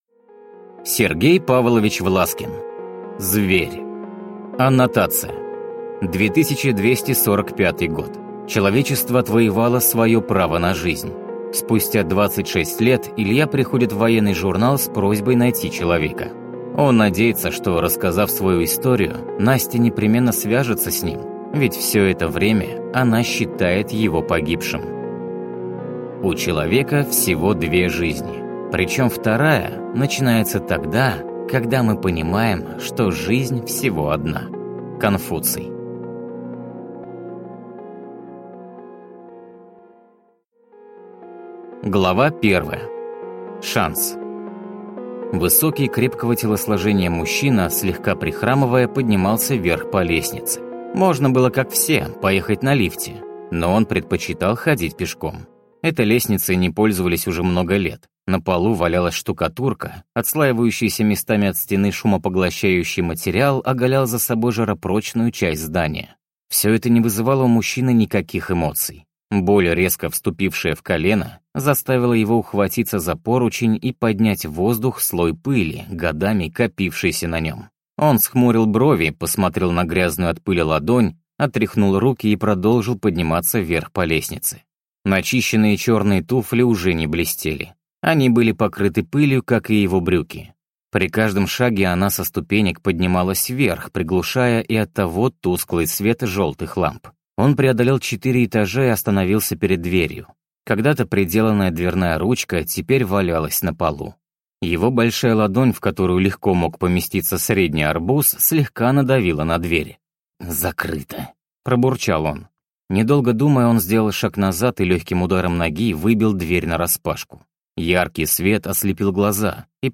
Аудиокнига Зверь | Библиотека аудиокниг